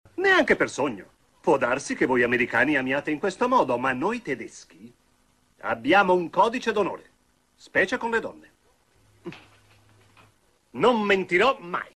nel telefilm "Gli eroi di Hogan", in cui doppia Werner Klemperer.